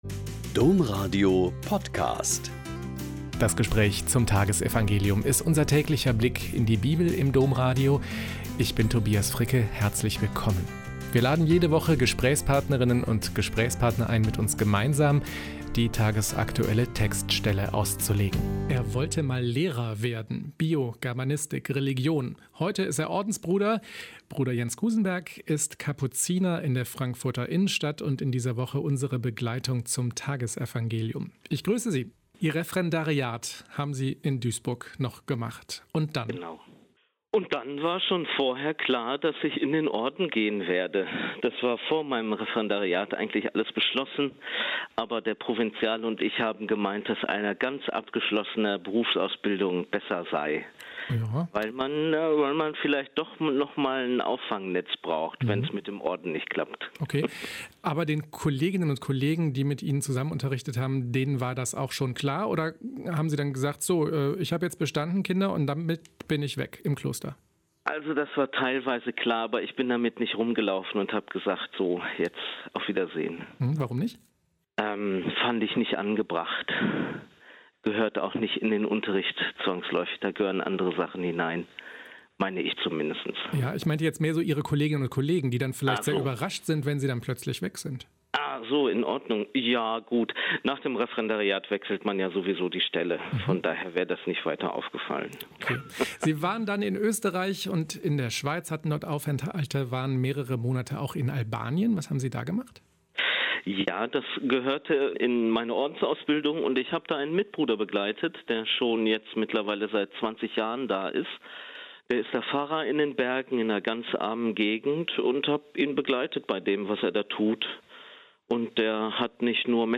Lk 10,38-42 - Gespräch